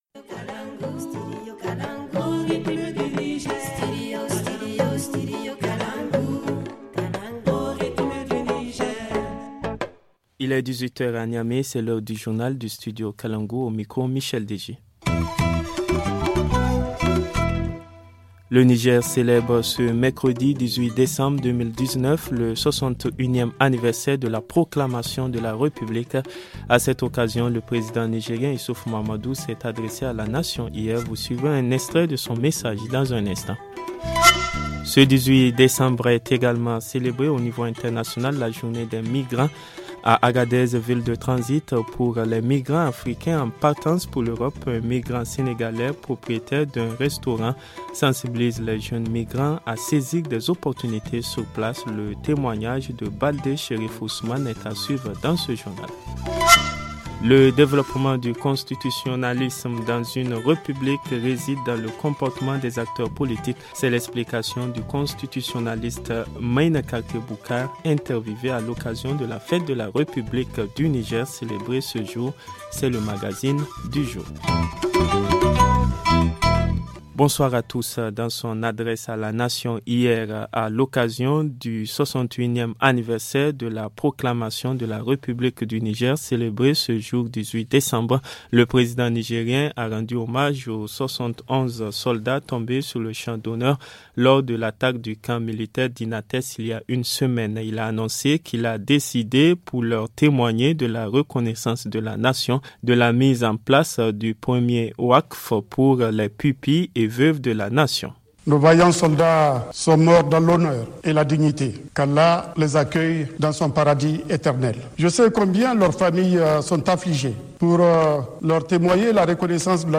Le journal du 18 decembre 2019 - Studio Kalangou - Au rythme du Niger